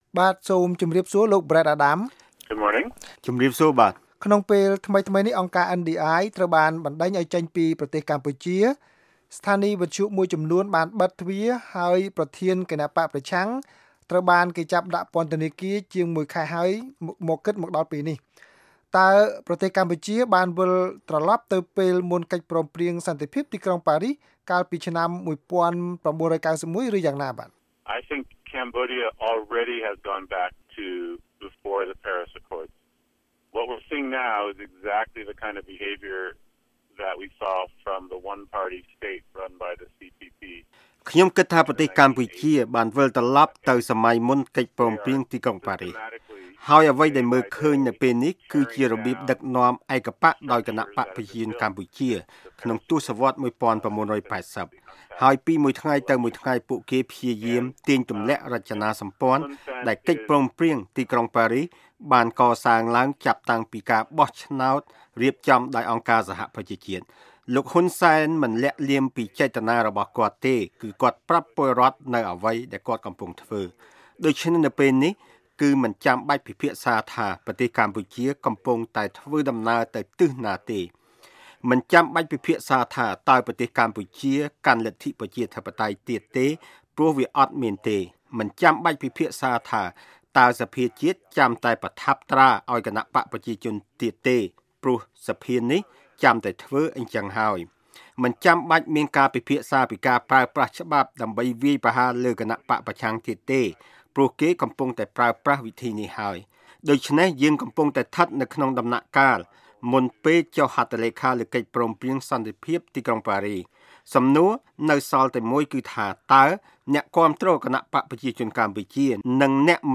បទសម្ភាសន៍ VOA៖ លោកប្រែដ អាដាម៖ កម្ពុជាវិលទៅការគ្រប់គ្រងមុនកិច្ចព្រមព្រៀងប៉ារីស